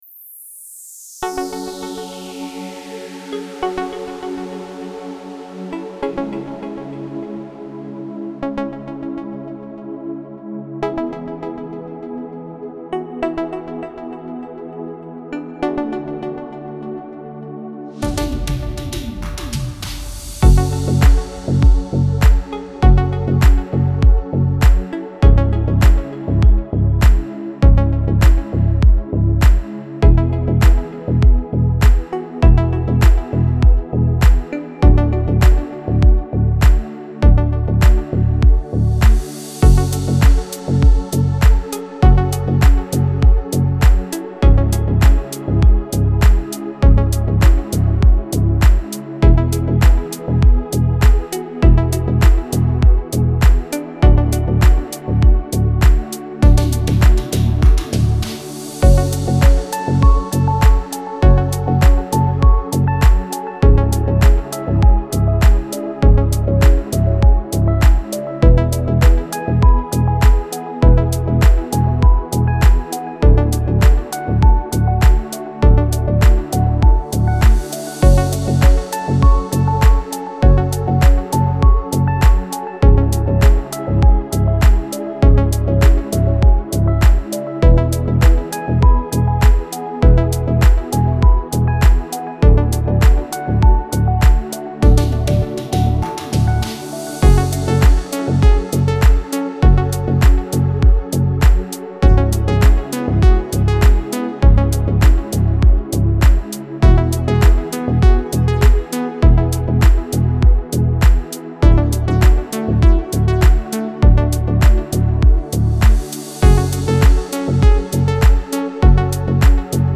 موسیقی کنار تو
سبک دیپ هاوس , ریتمیک آرام , موسیقی بی کلام